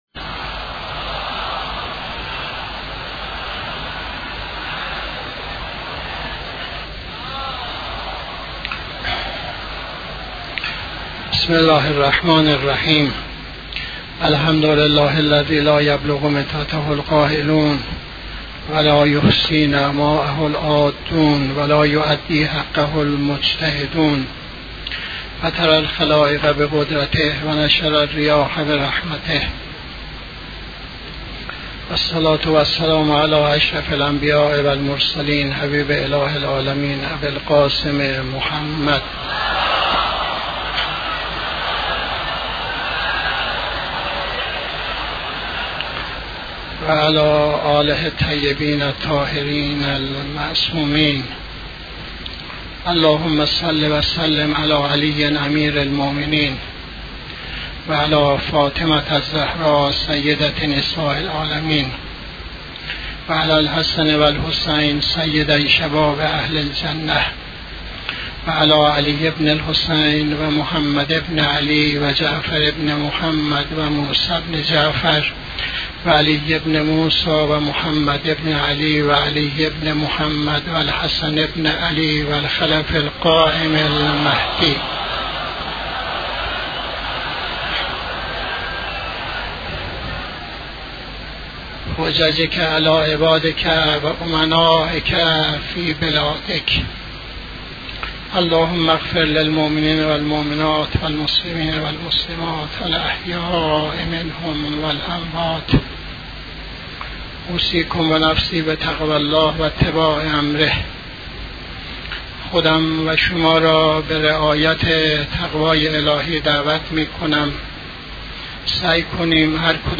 خطبه دوم نماز جمعه 21-12-83